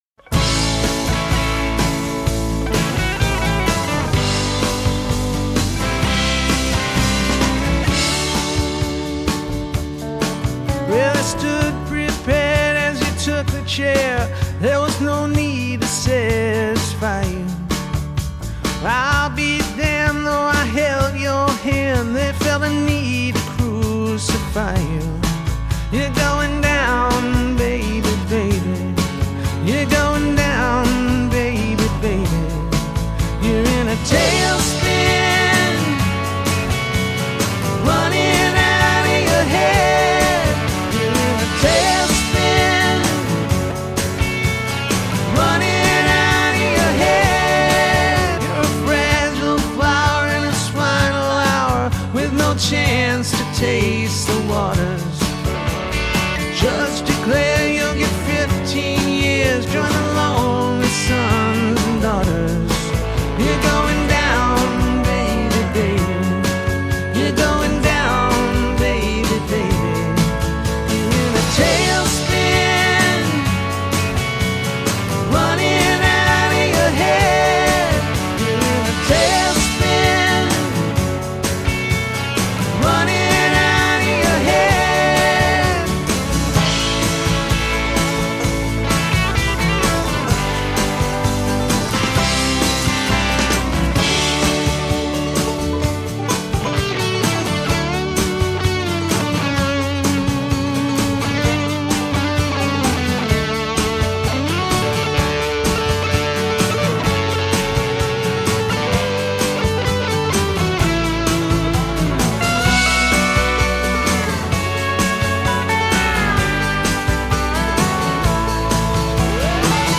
Alt-country
country-rock and jangle-pop
the sweet melodies and pop hooks remained intact